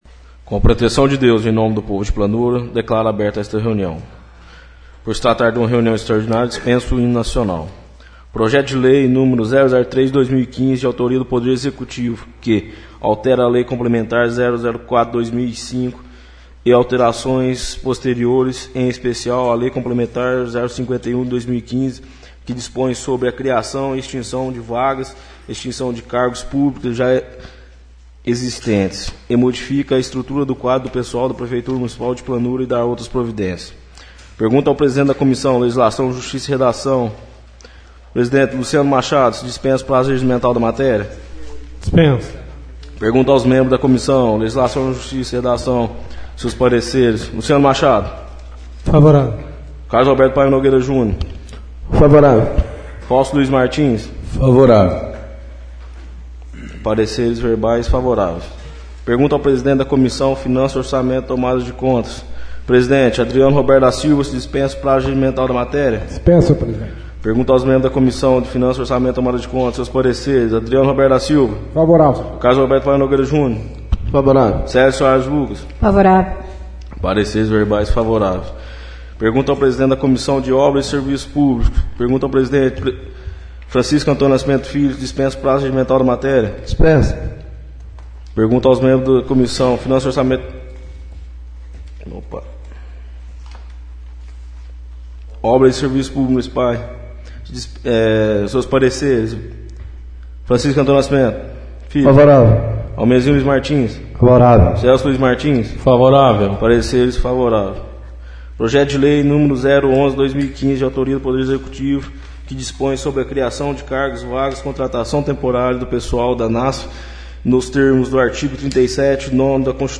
Sessão Extraordinária - 11/09/15 — CÂMARA MUNICIPAL DE PLANURA